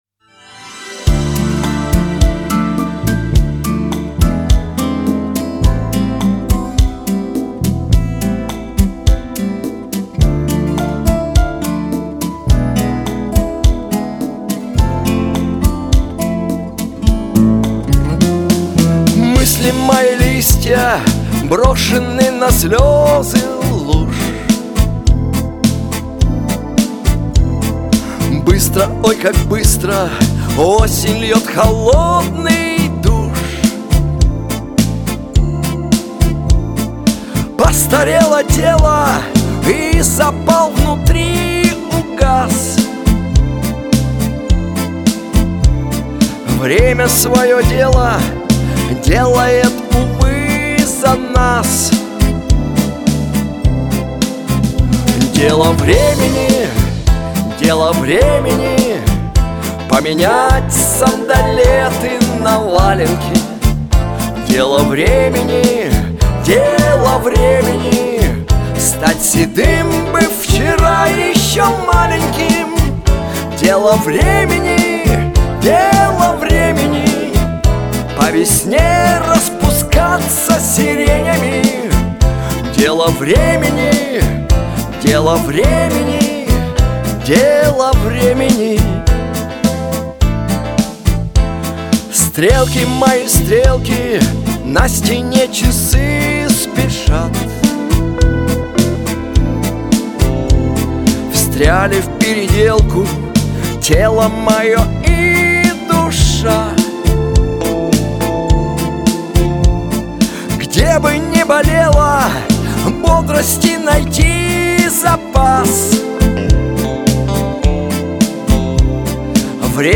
эстрада , pop
диско